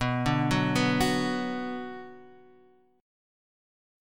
Bm chord